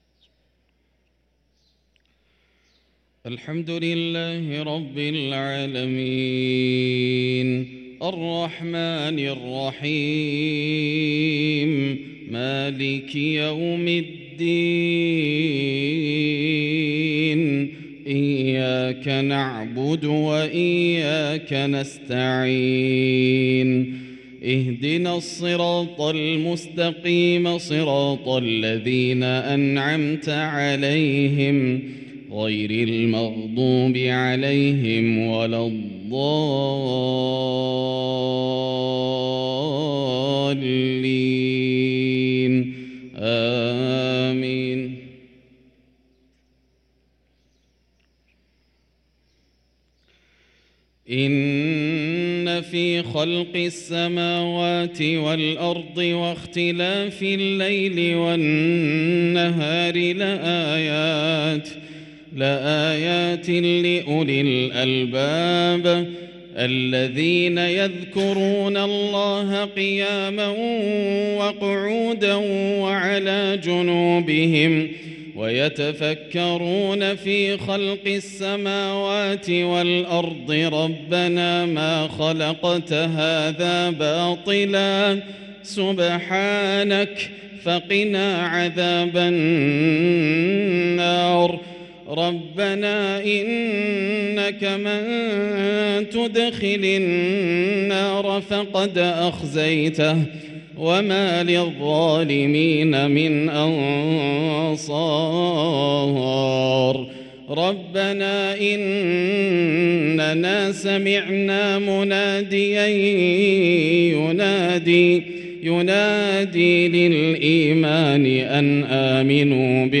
صلاة المغرب للقارئ ياسر الدوسري 11 شعبان 1444 هـ
تِلَاوَات الْحَرَمَيْن .